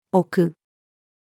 置く-female.mp3